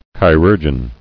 [chi·rur·geon]